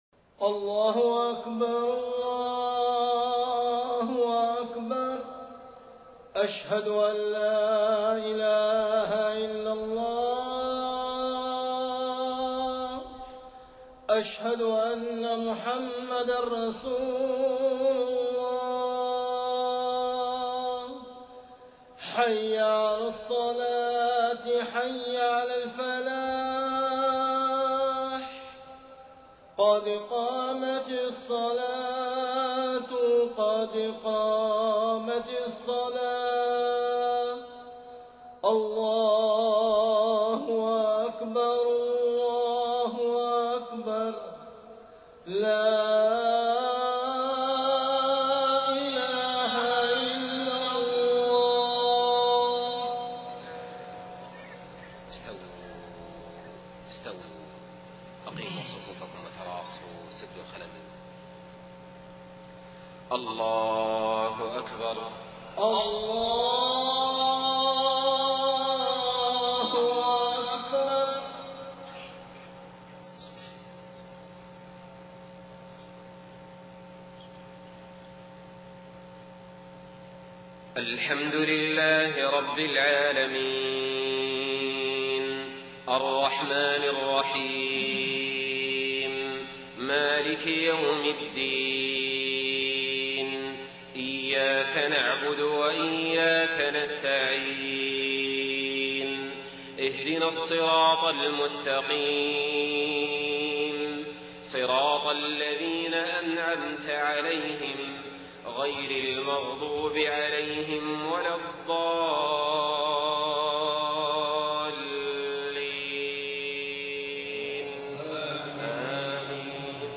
صلاة العشاء 13 محرم 1430هـ خواتيم سورتي يوسف 109-111 والصافات 171-182 > 1430 🕋 > الفروض - تلاوات الحرمين